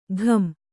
♪ ghat